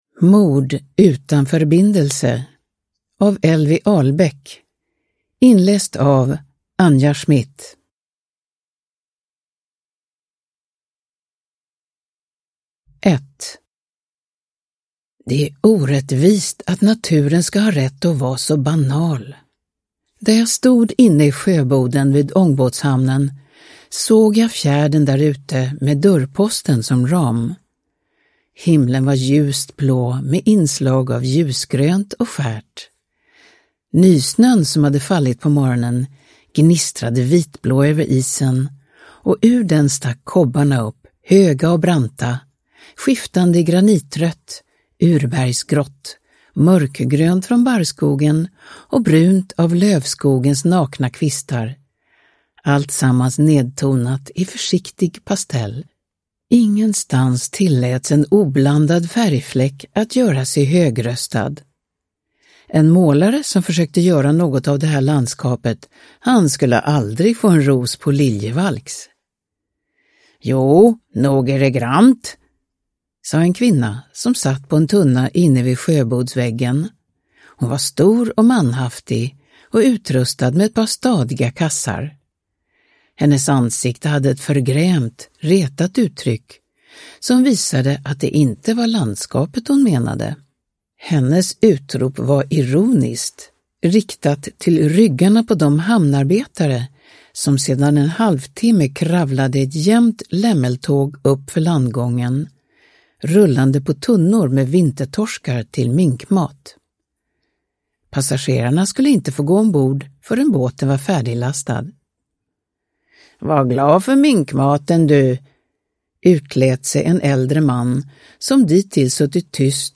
Mord utan förbindelse – Ljudbok – Laddas ner